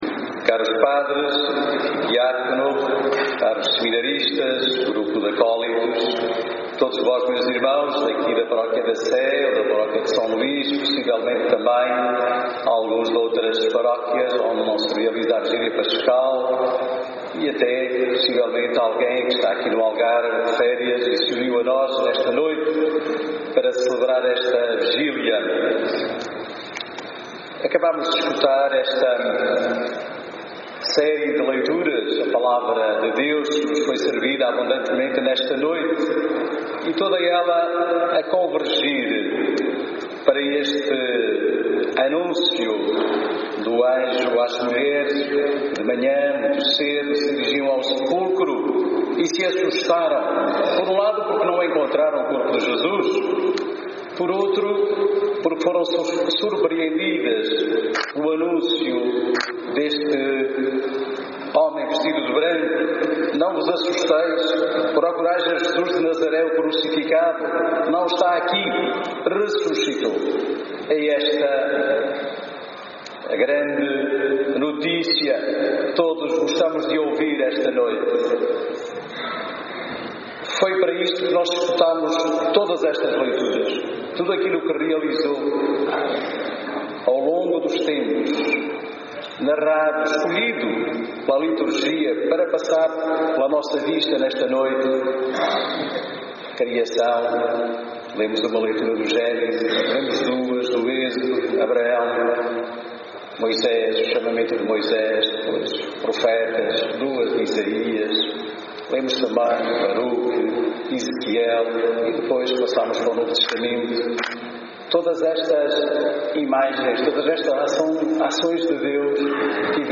Homilia_vigilia_pascal_2018.mp3